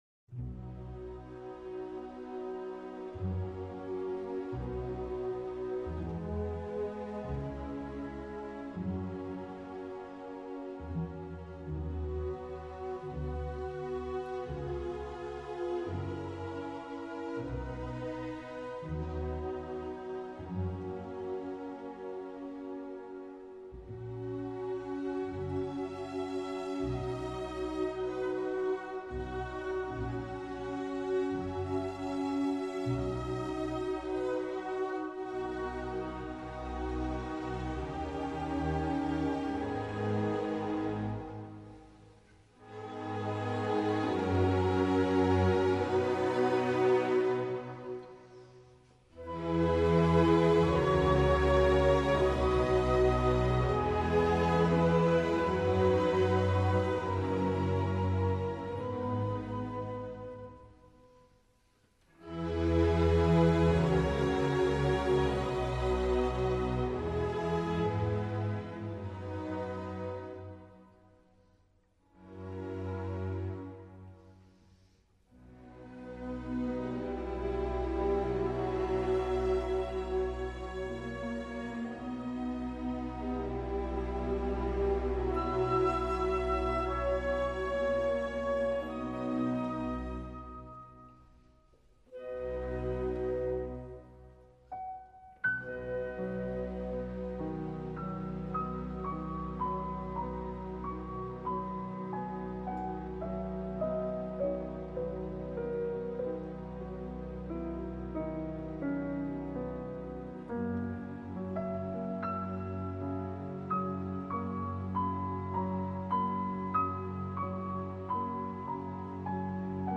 classical-piano-concerto-no-5-in-e-flat-major-op-73-emporor-pachelbel-bach-beethoven-schubert-mozart-dvora.mp3